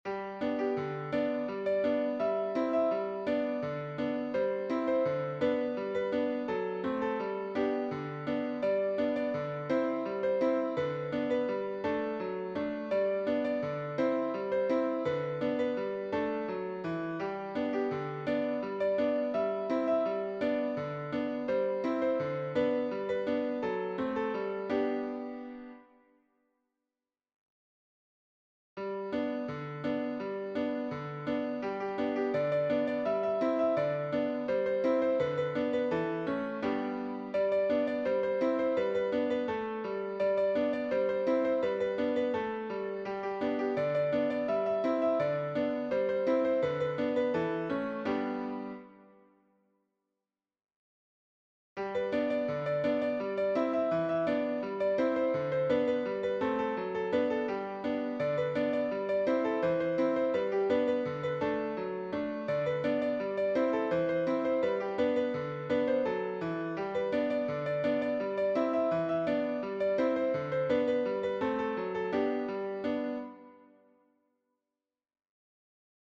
Cheerful
Piano only